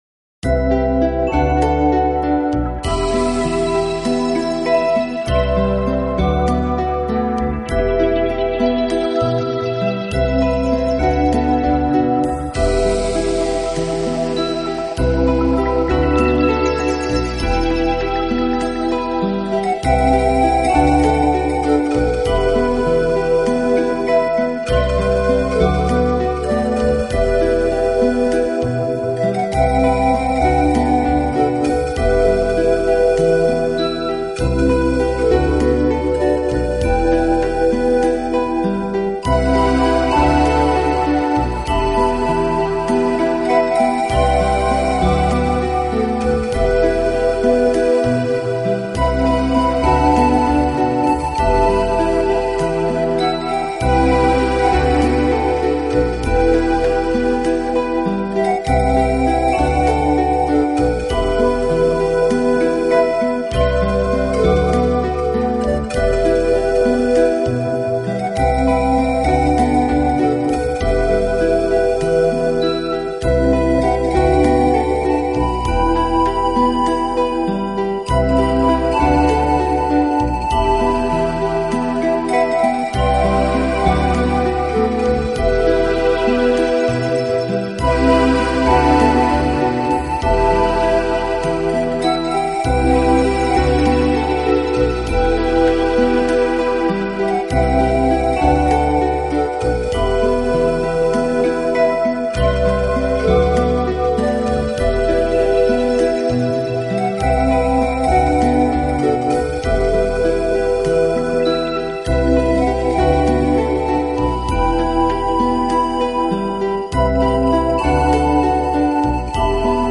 排笛专辑
对音乐旋律和自然声音的微妙结合是他成功的关键。